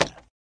woodice3.ogg